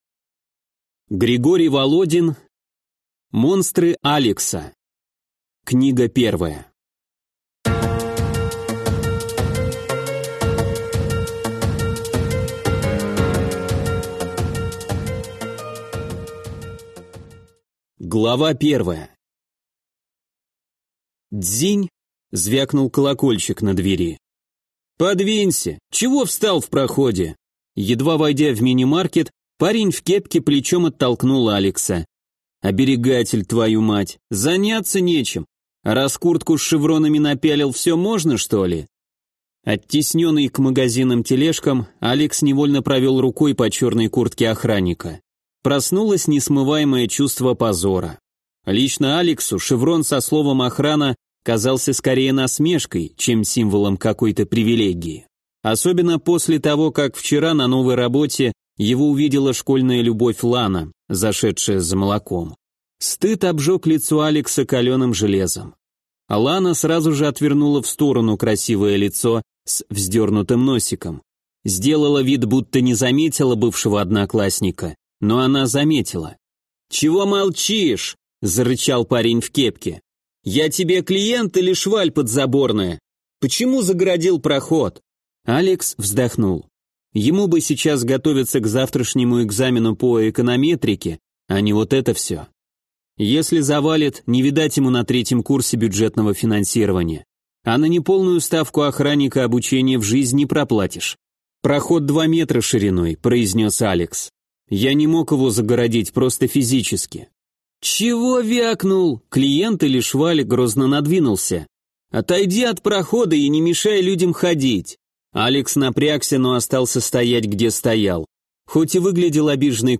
Аудиокнига Монстры Алекса. Том 1 | Библиотека аудиокниг